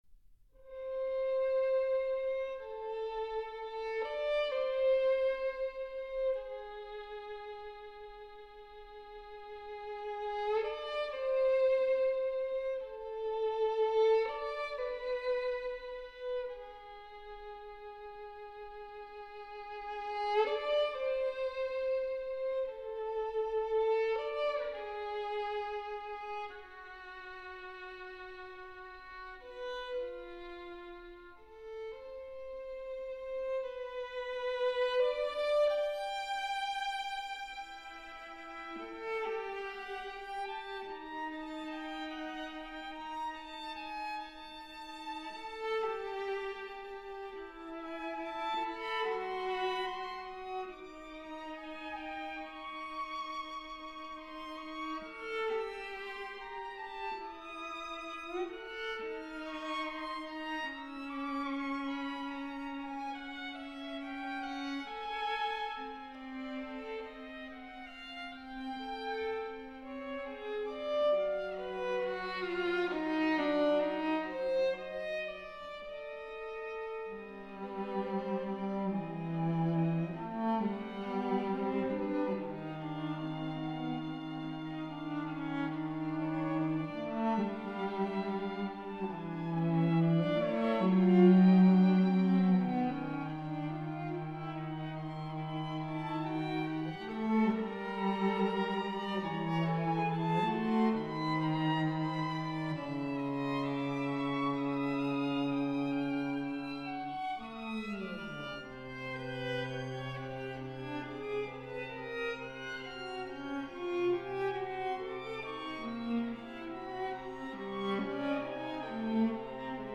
ADAGIO (750)